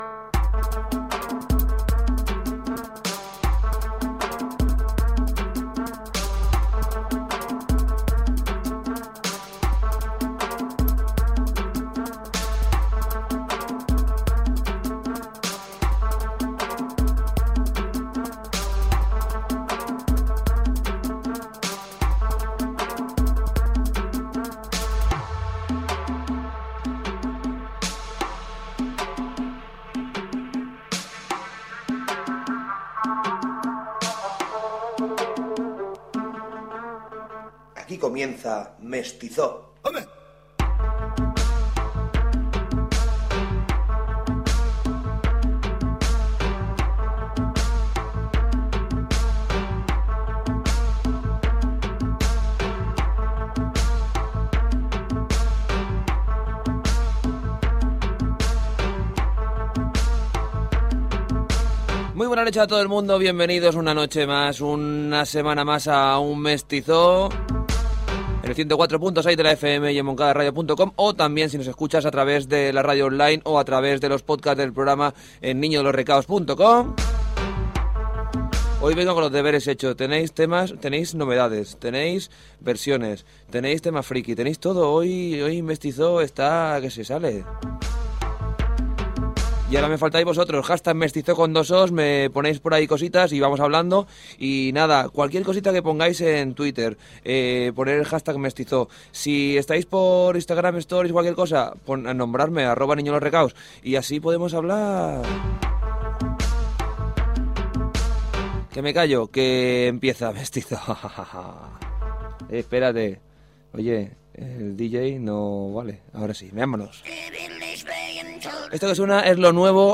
Sintonia, identificació de l'emissora, contacte a les xarxes socials i tema musical.
Musical